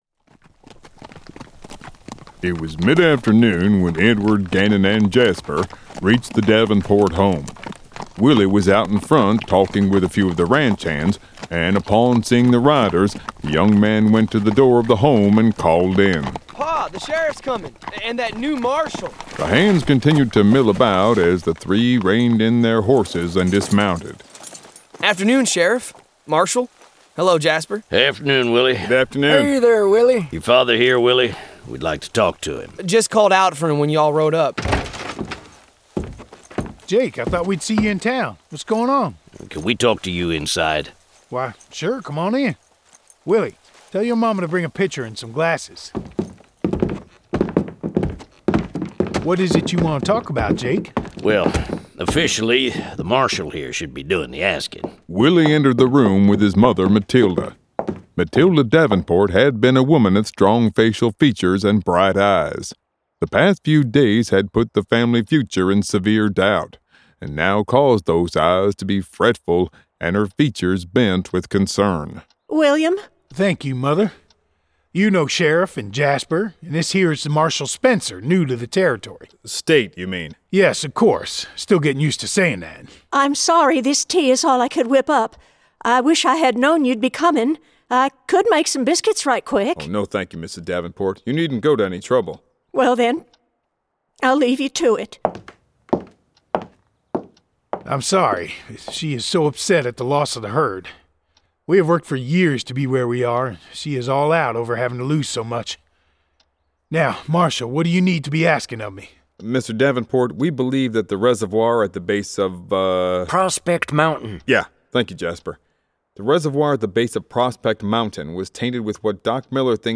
This is an actively updated collection of graphic audio material.